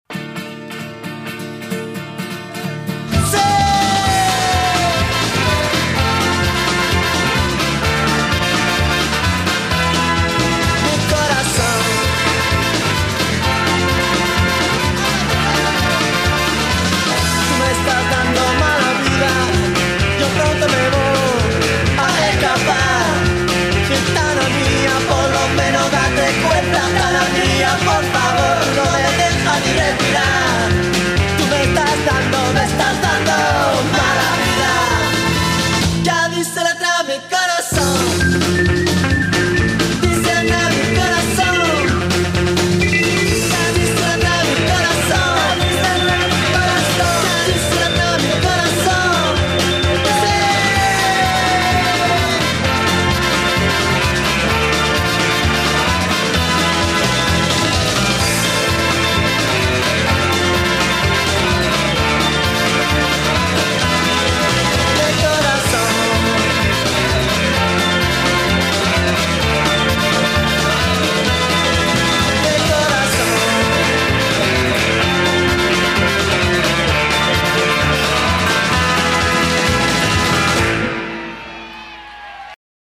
Le Jingle